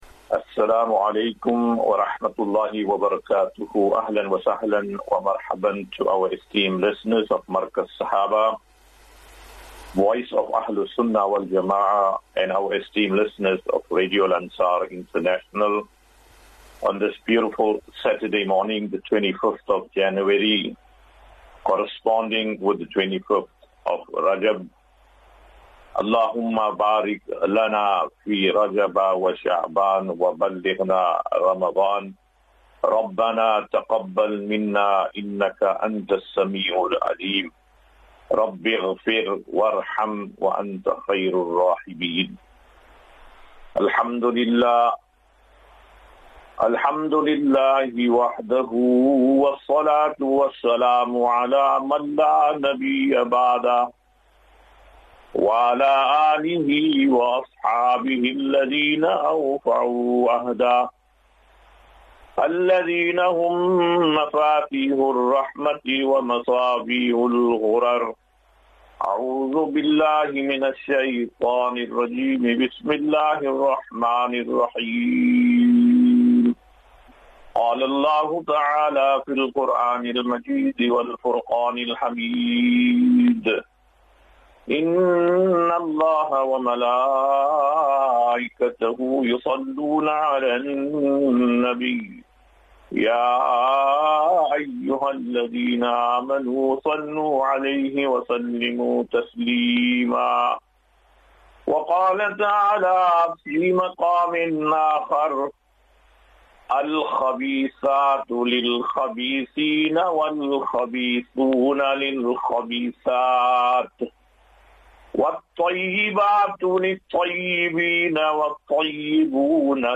Daily Naseeha.
As Safinatu Ilal Jannah Naseeha and Q and A 25 Jan 25 January 2025.